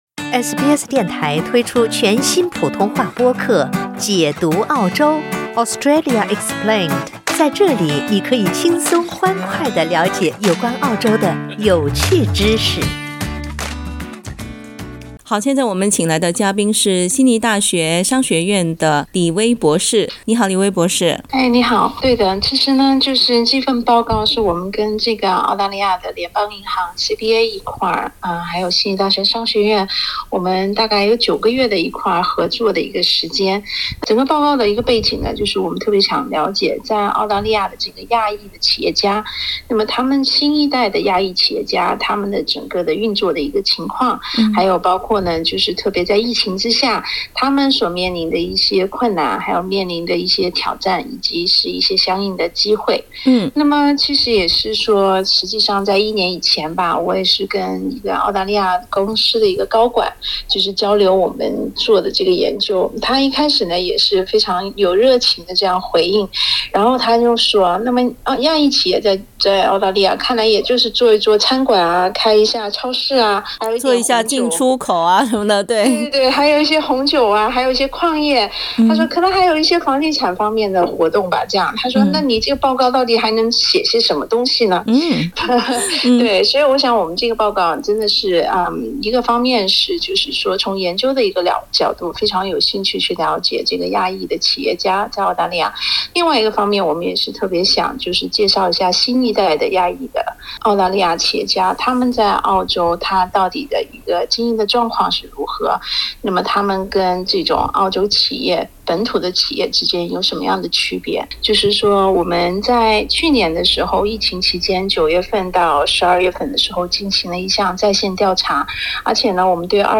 悉尼大學商學院和澳大利亞聯邦銀行推出一份研究報告：《年輕的亞裔澳大利亞企業推動了彊大的全球聯系》（Young Asian-Australian businesses drive strong global links: report） 該報告指出：新一代亞裔澳大利亞人涉足服務、科技等新行業，而不僅是為在澳社區提供餐飲等服務。（點擊圖片收聽寀訪）